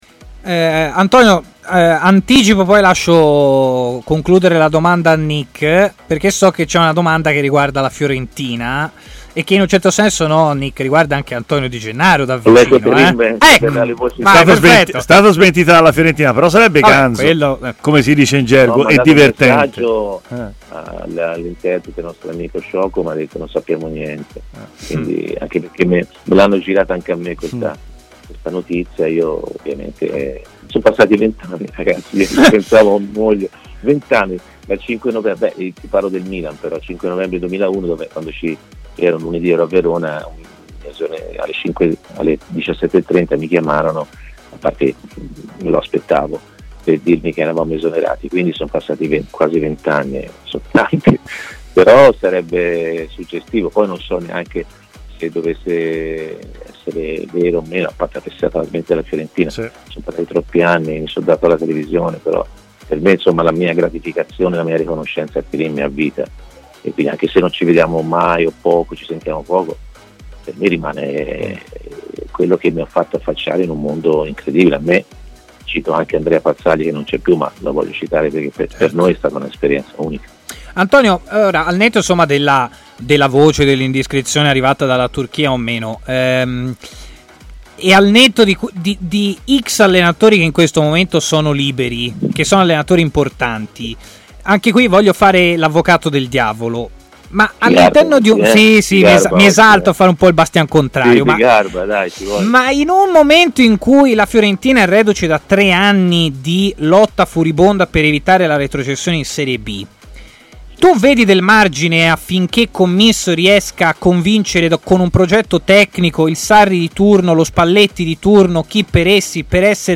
L'ex centrocampista Antonio Di Gennaro, commentatore tv per la Rai e opinionista di TMW Radio, è intervenuto durante Stadio Aperto per parlare anche del momento che sta vivendo la Fiorentina.